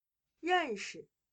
认识/Rénshi/(Sust.) saber, comprender, reconocer.